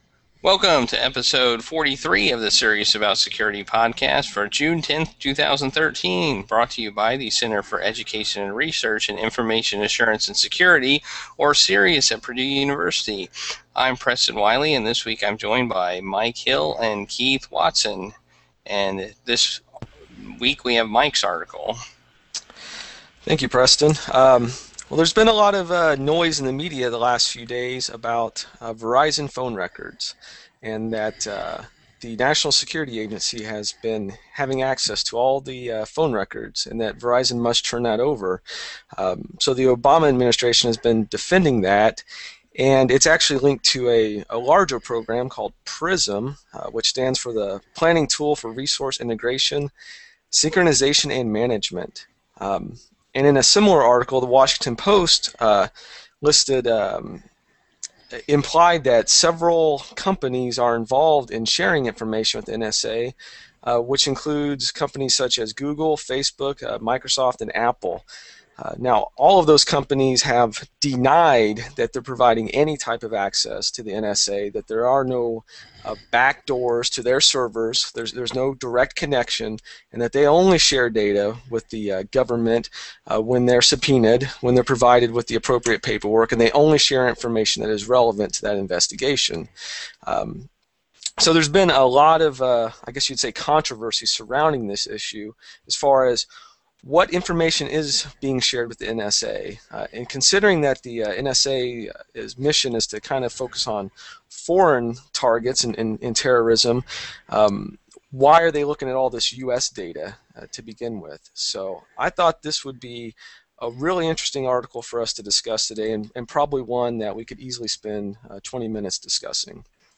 Google+ Hangout